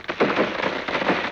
Index of /90_sSampleCDs/E-MU Producer Series Vol. 3 – Hollywood Sound Effects/Water/Falling Branches
LIMB CRAC04R.wav